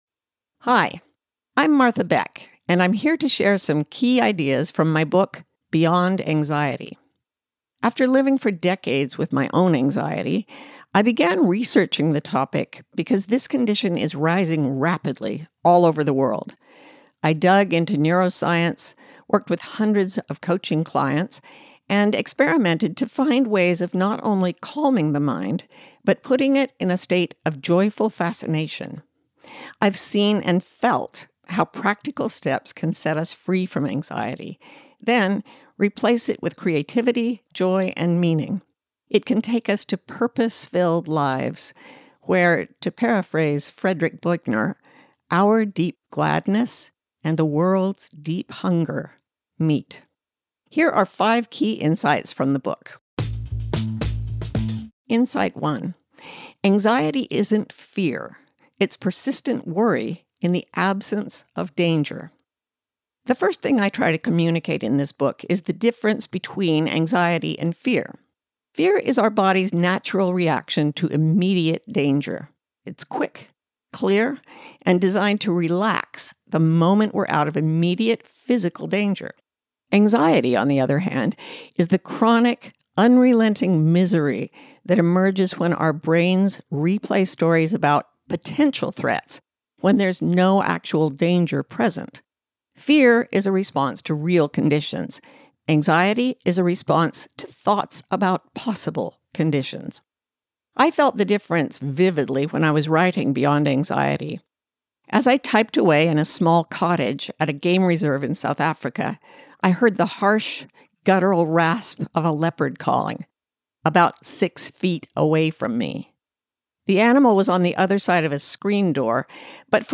Listen to the audio version—read by Martha herself—in the Next Big Idea App.